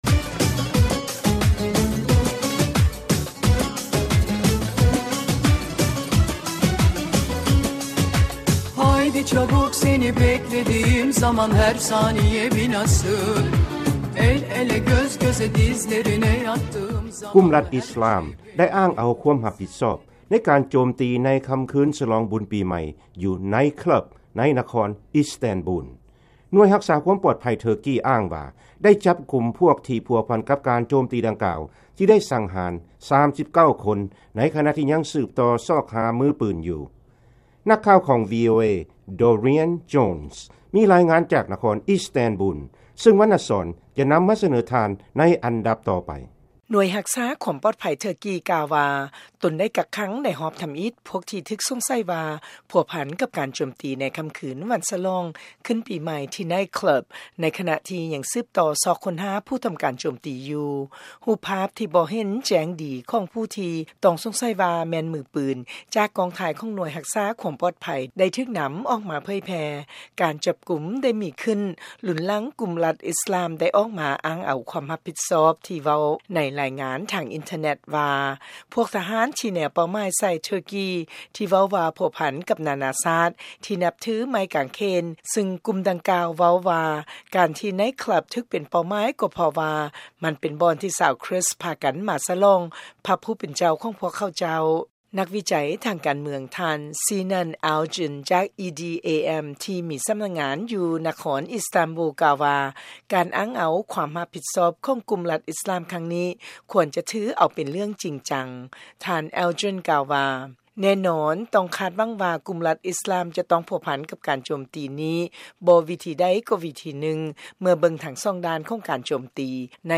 ((FUNERAL ACT IN & UNDER))